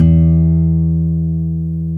Index of /90_sSampleCDs/Roland L-CD701/GTR_Nylon String/GTR_Nylon Chorus
GTR NYL3 E0H.wav